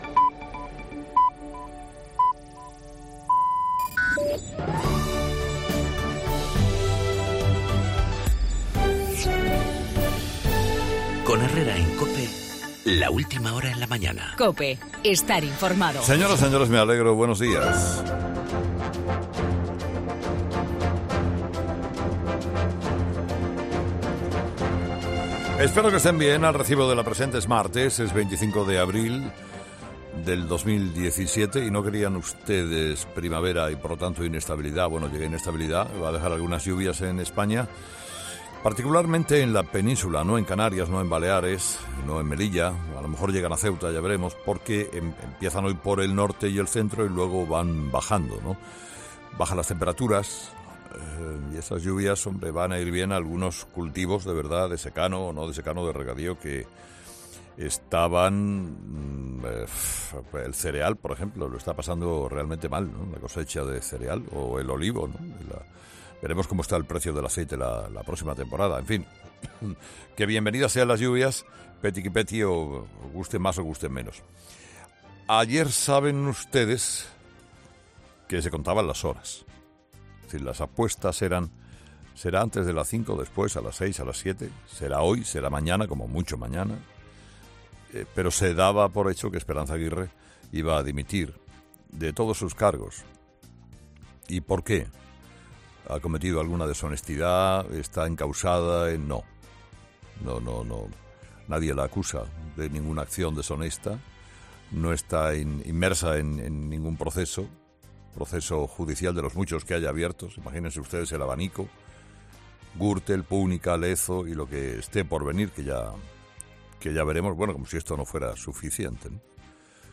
AUDIO: La dimisión de Esperanza Aguirre y la investigación en la operación Lezo, en el monólogo de Carlos Herrera a las 8 de la mañana.